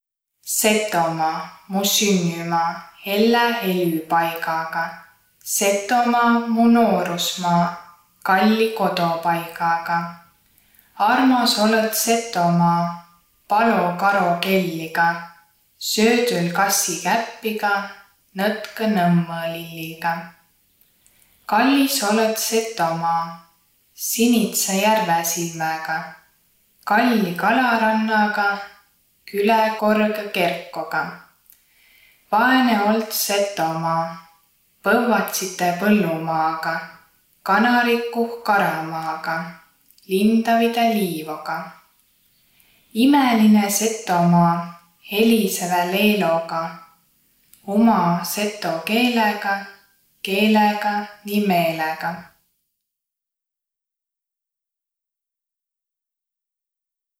Luulõtus “Setomaa”
Peri plaadilt juttõ ja laulõ seto aabitsa mano.
luulõtus_Setomaa.wav